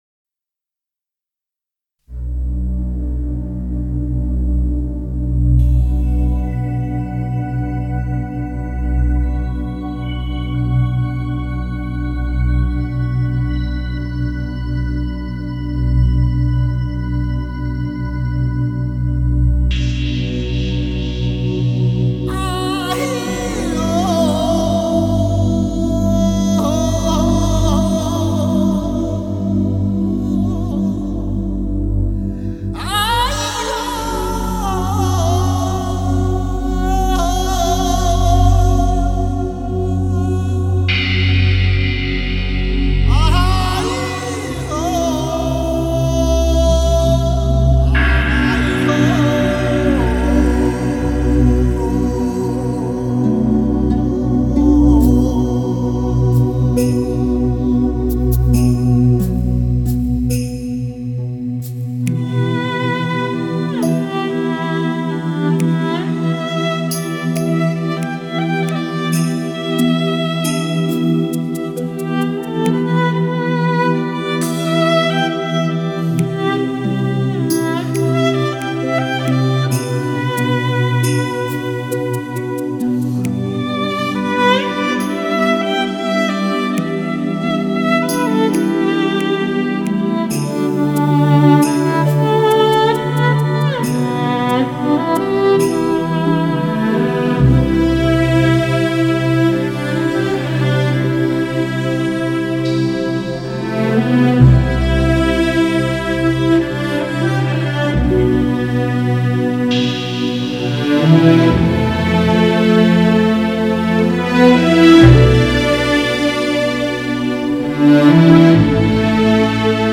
3周前 纯音乐 8